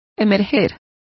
Complete with pronunciation of the translation of emerge.